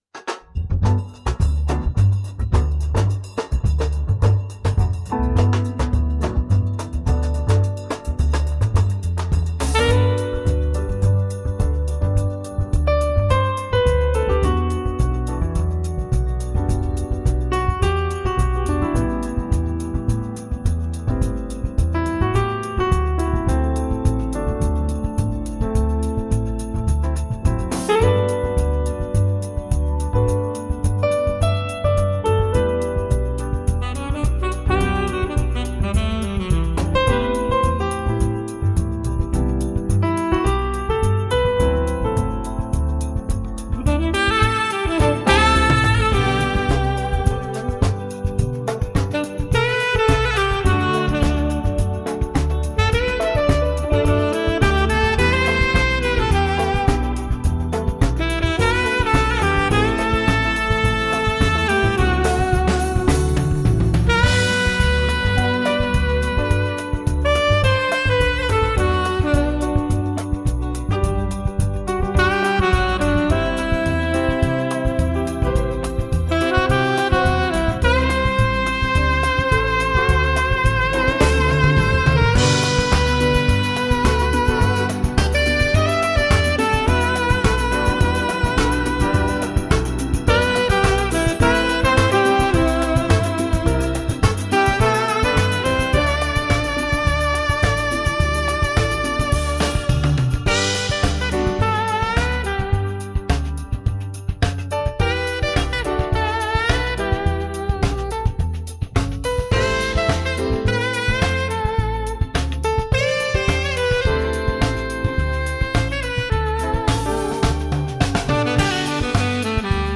そんな時にしっとりと流れていたような曲を想像しながら作ってみました。